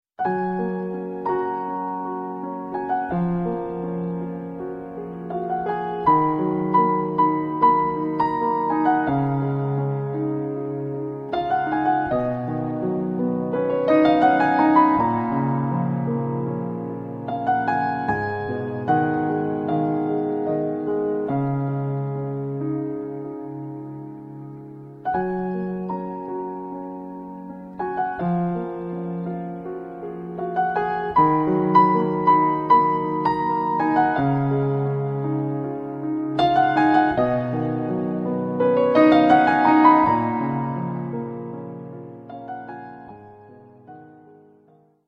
Very soothing solo piano pieces